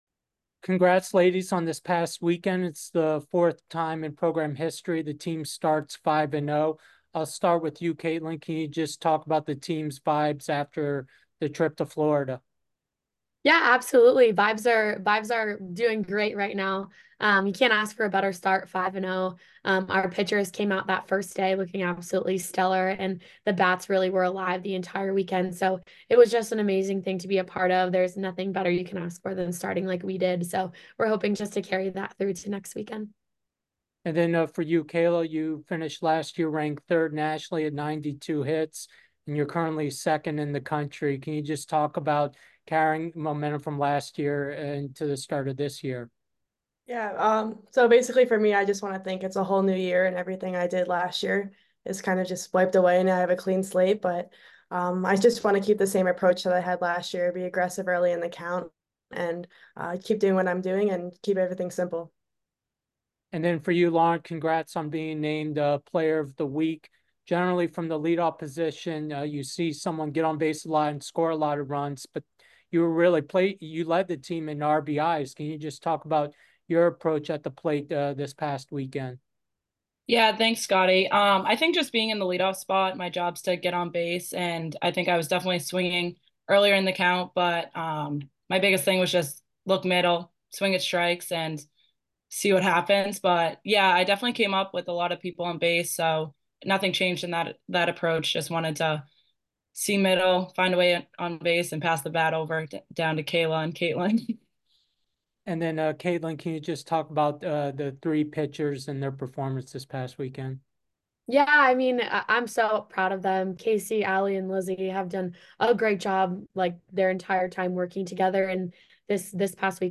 Softball / Week 1 Recap Interview (2-13-24) - Boston University Athletics